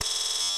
sci-fi_computer_running_code_02.wav